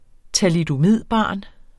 Udtale [ talidoˈmiðˀˌ- ]